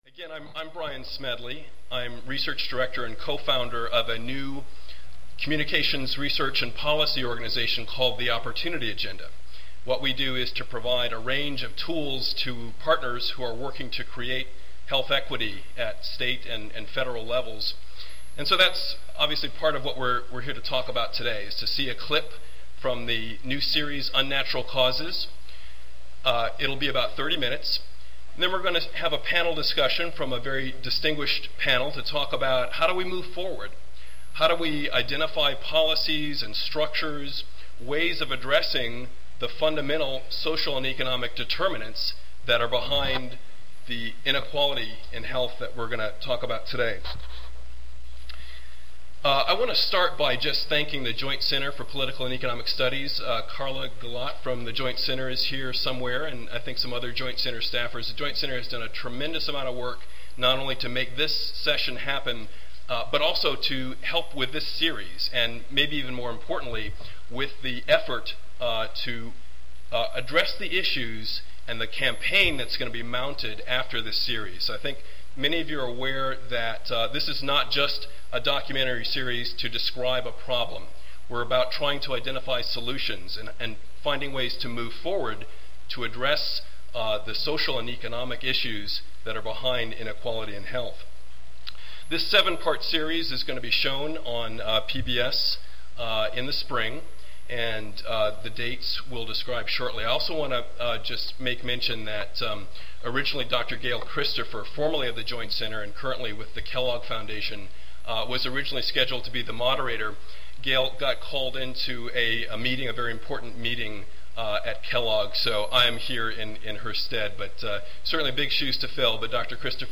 The screening will be followed by a structured dialog between a leading health equity scholar, practitioner, and member of Congress.
There will be time for discussion and interaction with the audience.